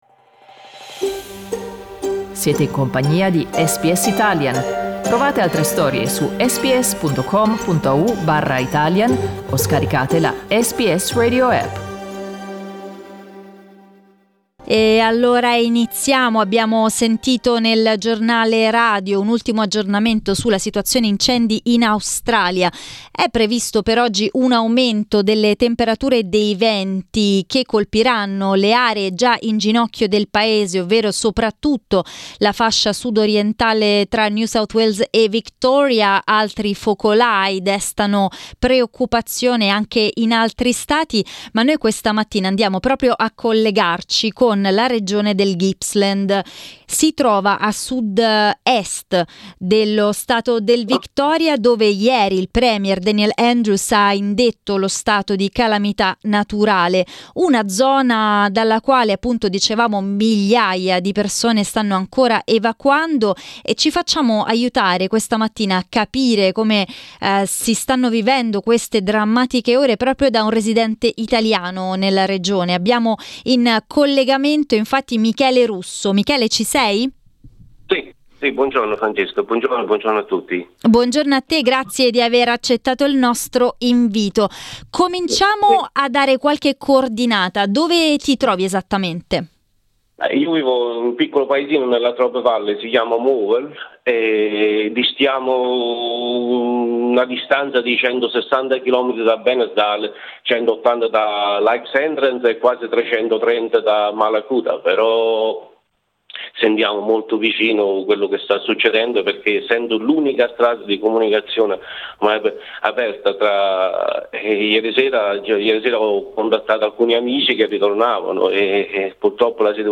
in diretta da Morwell